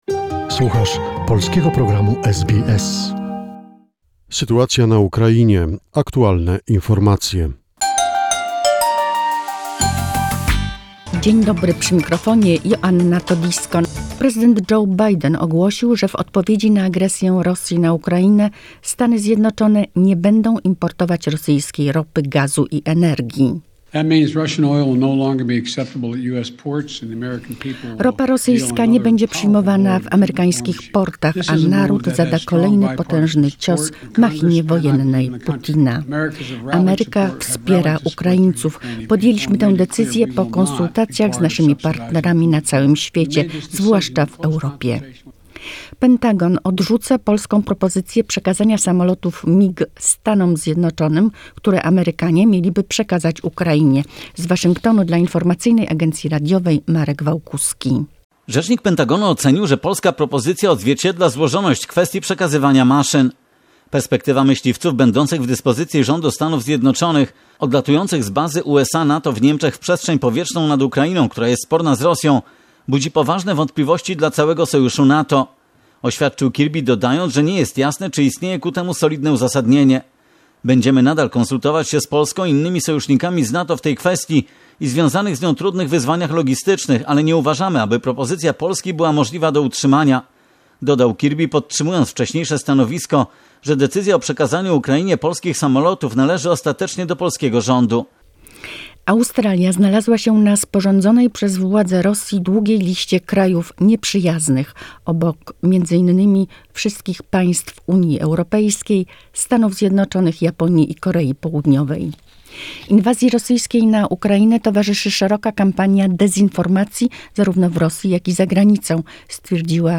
The most recent information and events about the situation in Ukraine, a short report prepared by SBS Polish.